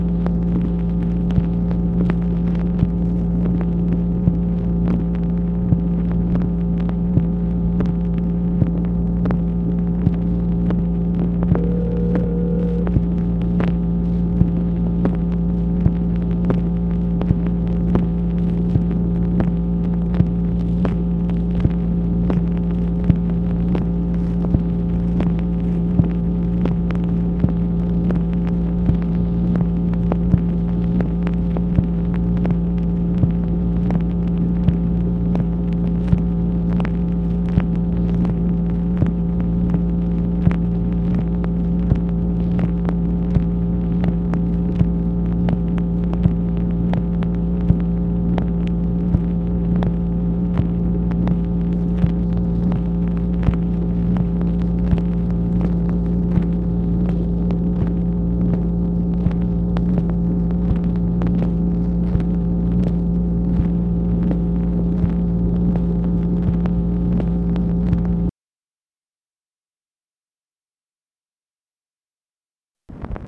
MACHINE NOISE, September 23, 1966
Secret White House Tapes | Lyndon B. Johnson Presidency